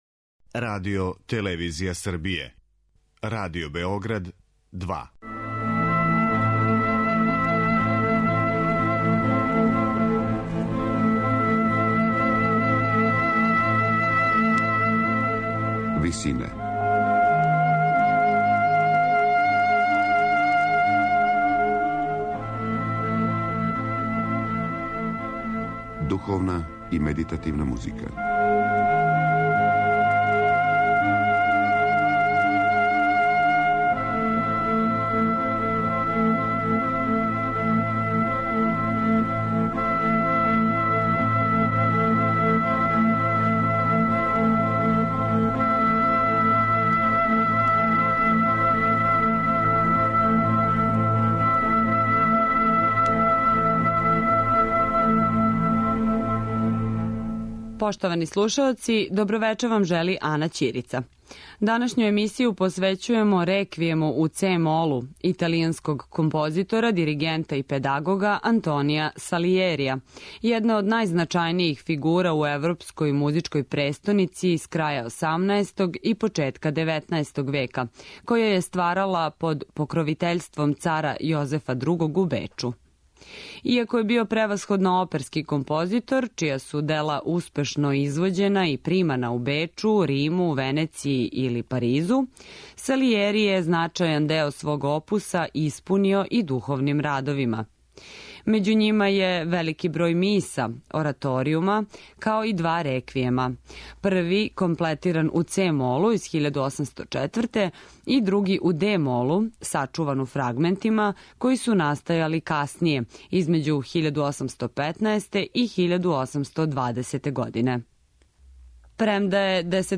Антонио Салијери: Реквијем у це-молу